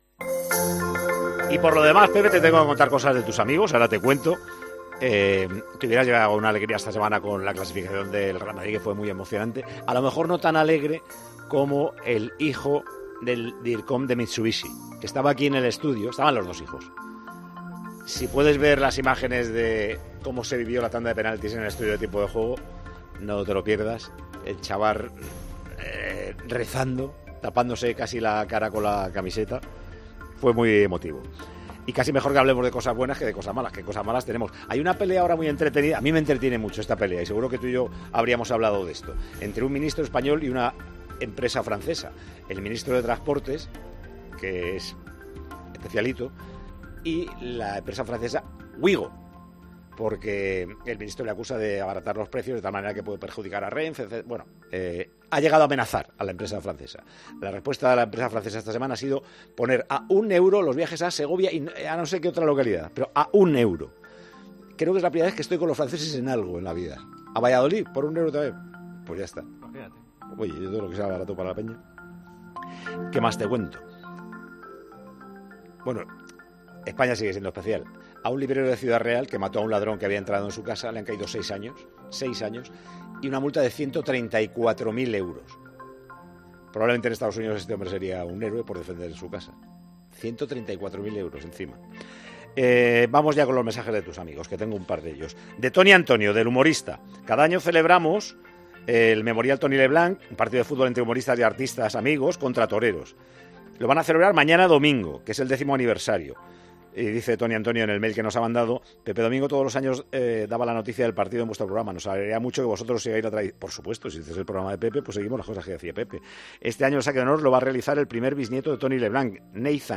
El director de Tiempo de Juego le hace un repaso a Pepe Domingo Castaño de cómo ha sido la semana en España y le cuenta un par de iniciativas de dos amigos suyos.